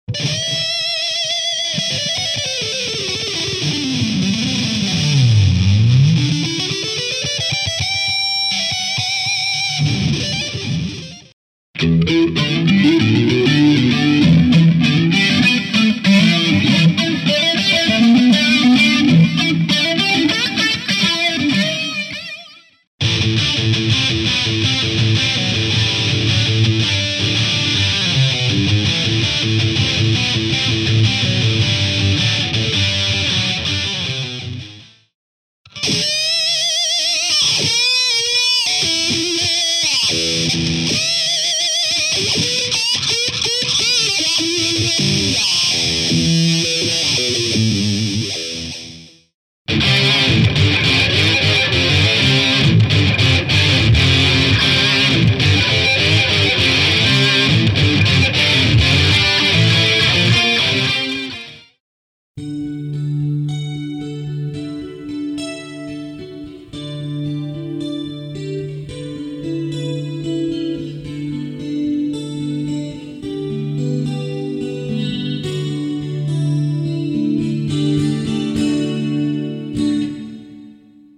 Guitar effect processor (2000)
guitar ring modulator